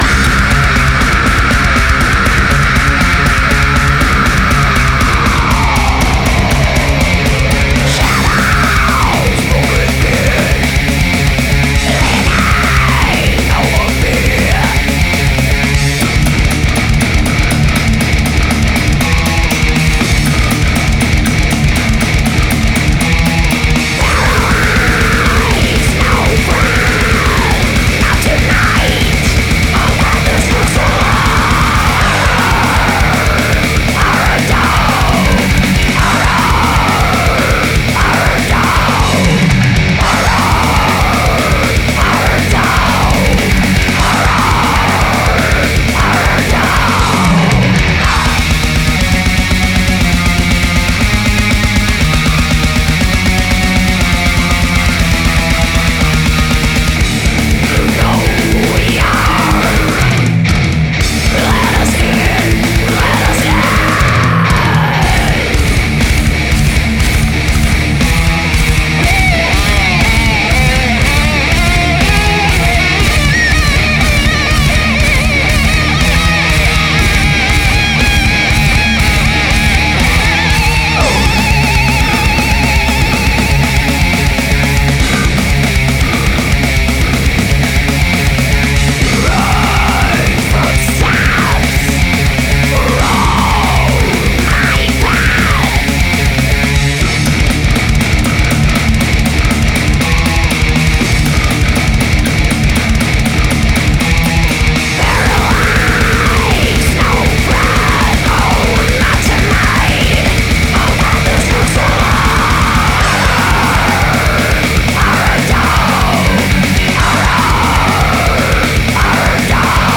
Это блекметалл.